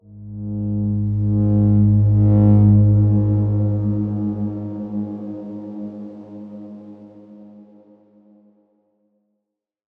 X_Darkswarm-G#1-pp.wav